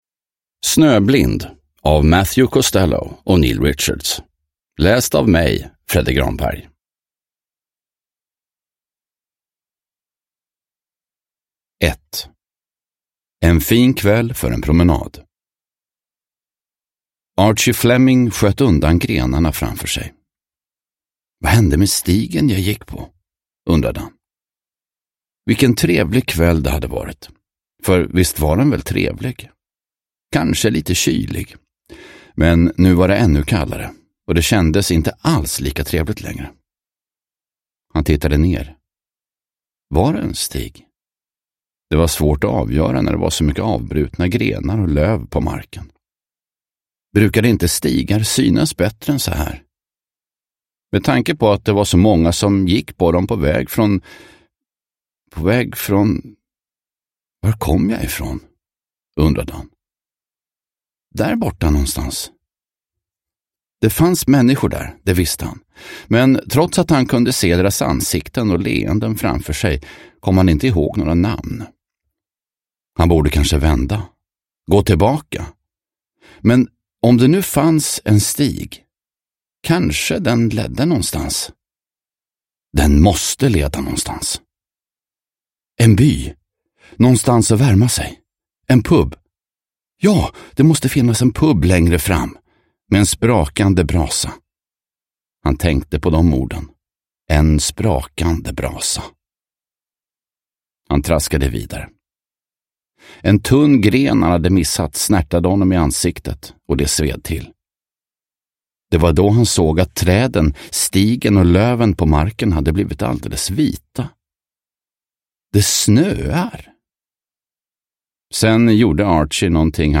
Snöblind – Ljudbok – Laddas ner